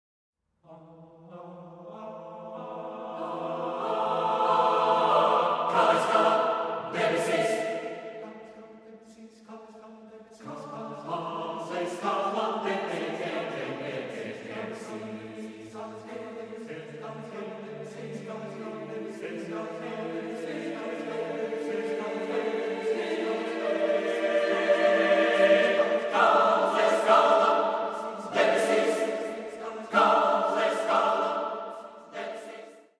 kora miniatūra